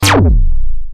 portal.wav